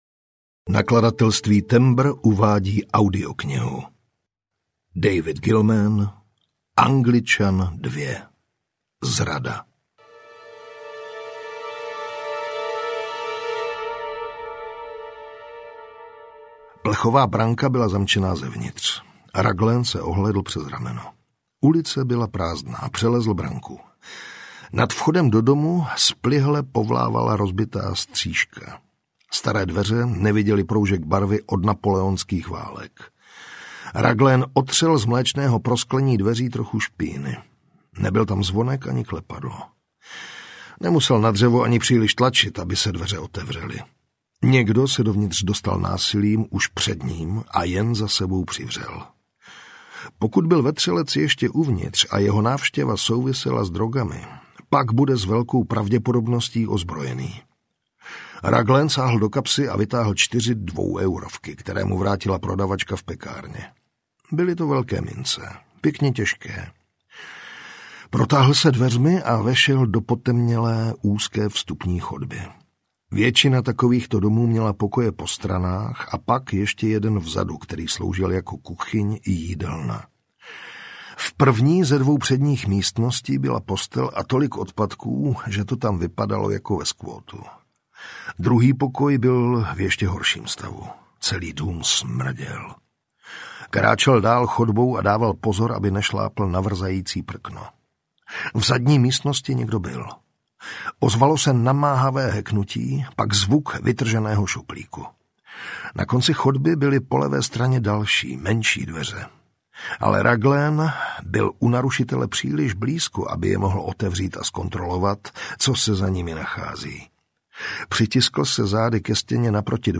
Zrada audiokniha
Ukázka z knihy
• InterpretJiří Vyorálek
zrada-audiokniha